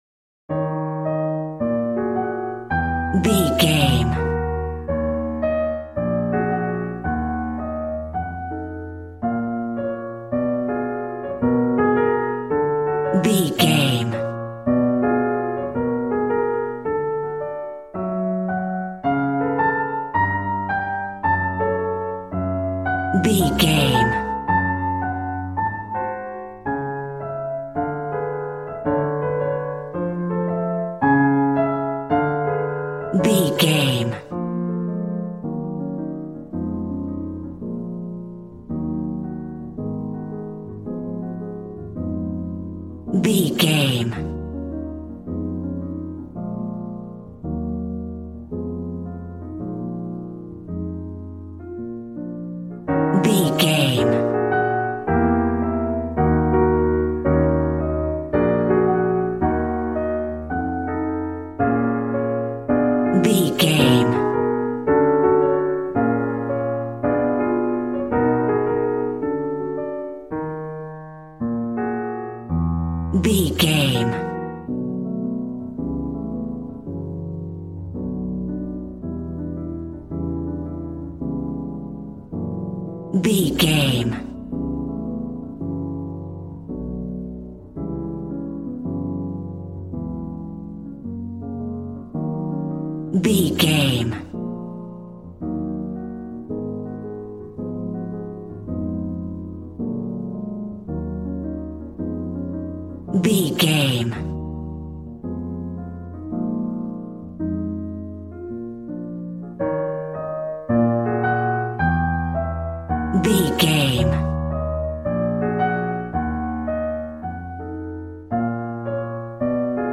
Smooth jazz piano mixed with jazz bass and cool jazz drums.,
Aeolian/Minor
E♭